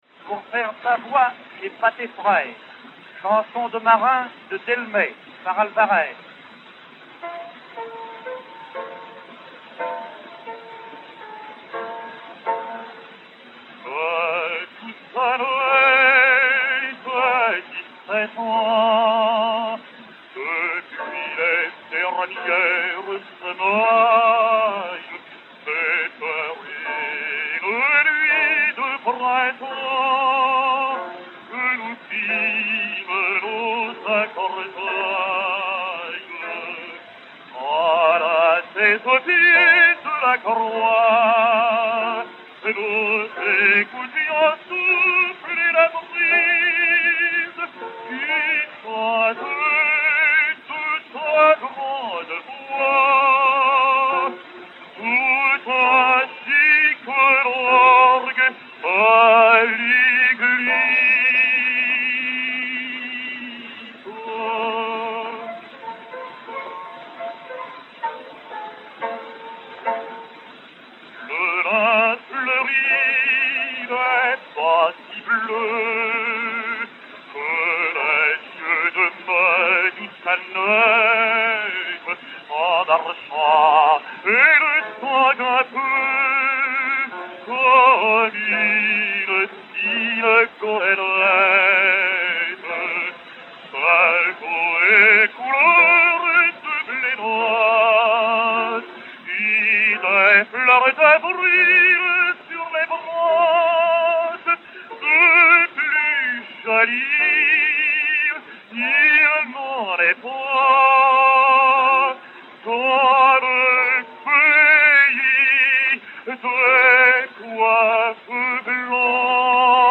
Francesco Tamagno (Otello) et Piano
Disque Pour Gramophone 52101, enr. à Milan en 1905